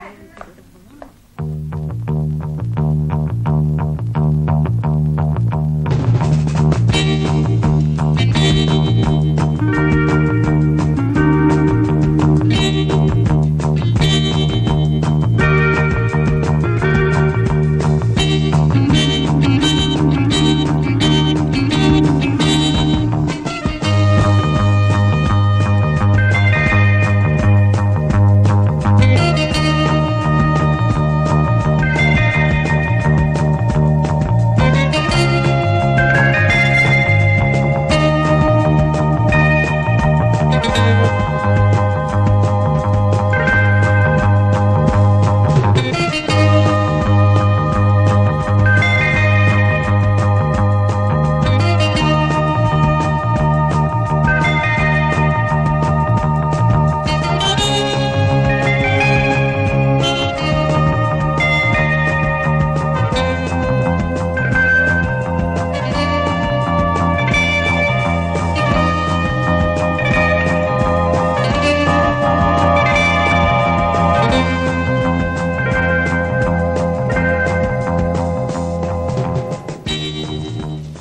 фрагмент инструментовки